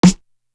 Ninety One Snare.wav